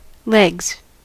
Ääntäminen
Ääntäminen US : IPA : [lɛɡz] Haettu sana löytyi näillä lähdekielillä: englanti Käännöksiä ei löytynyt valitulle kohdekielelle.